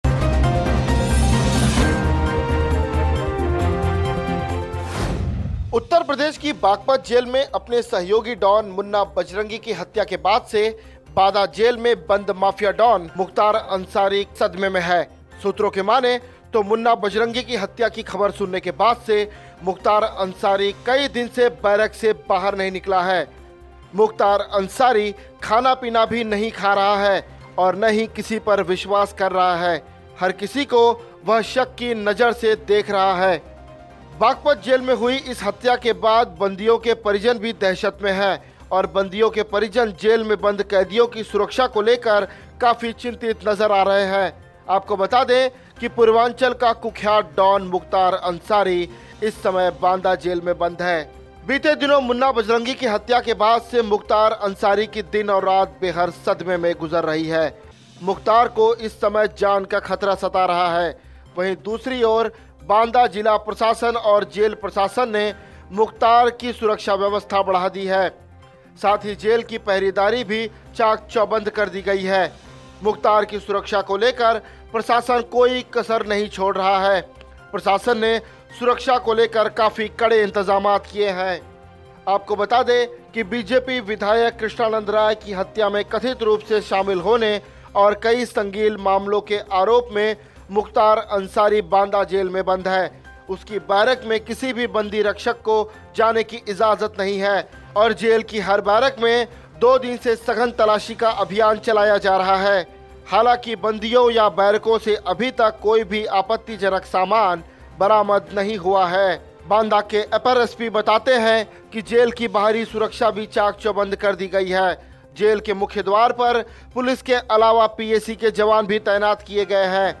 न्यूज़ रिपोर्ट - News Report Hindi / मुख्तार अंसारी माफिया डॉन कैसे बना, मुन्ना बजरंगी की हत्या के बाद उड़ी अंसारी की नींद